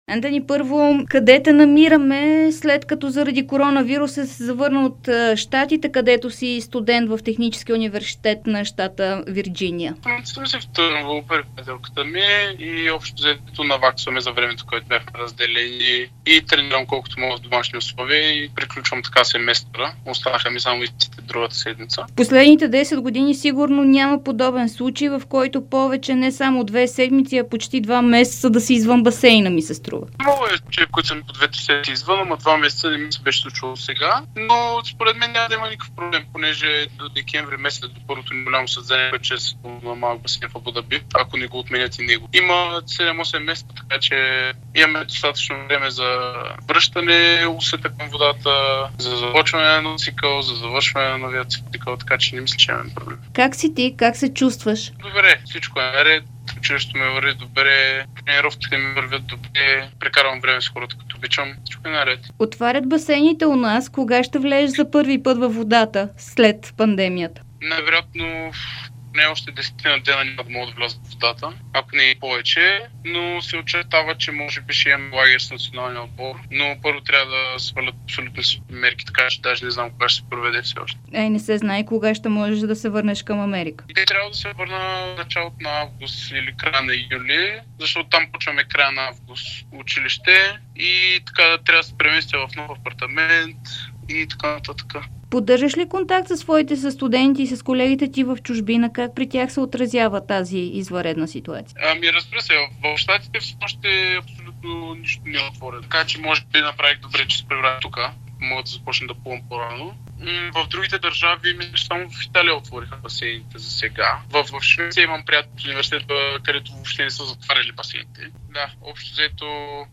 Българската звезда в плуването Антъни Иванов даде специално интервю за dsport и Дарик радио, в което говори за случващото се с него в периода на коронавирус.